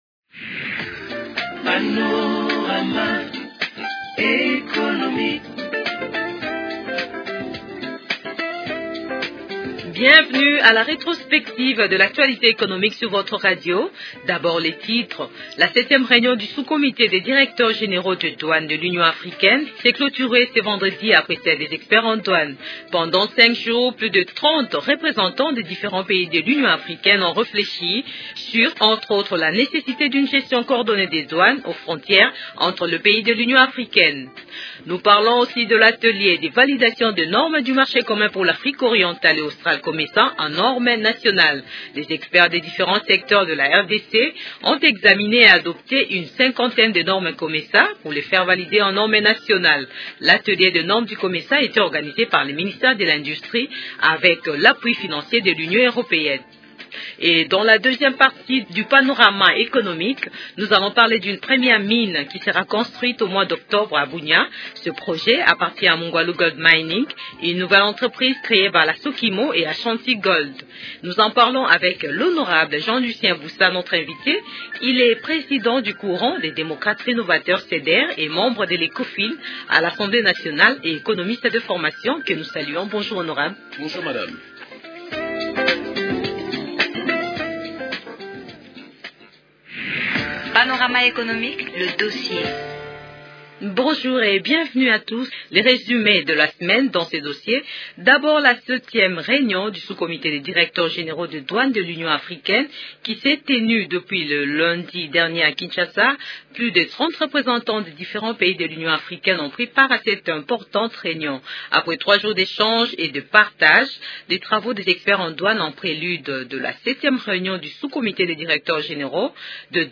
Pour analyser l'actualité économique de la semaine, le magazine a reçu le député Jean-Lucien Busa.